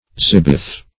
zibeth - definition of zibeth - synonyms, pronunciation, spelling from Free Dictionary
Zibet \Zib"et\, Zibeth \Zib"eth\, n. [Cf. It. zibetto. See